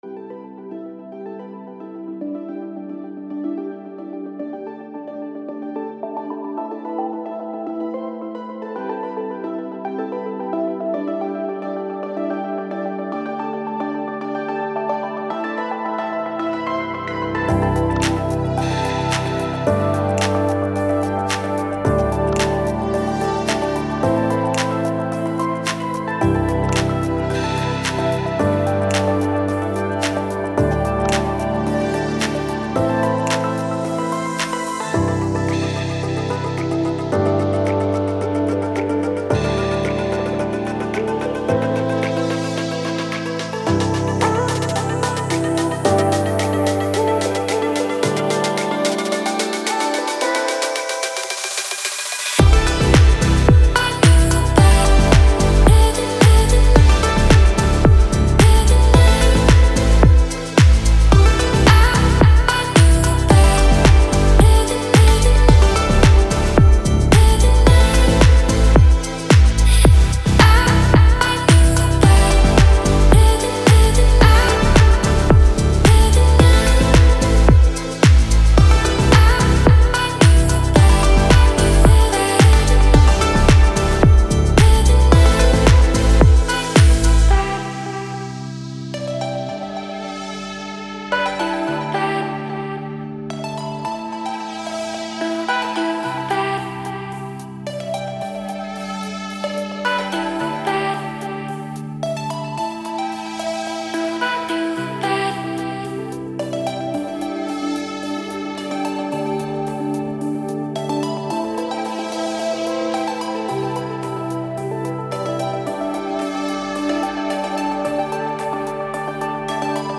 Electronic Dance Music